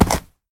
mob / horse / jump.ogg
jump.ogg